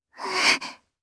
Lavril-Vox_Casting1_jp.wav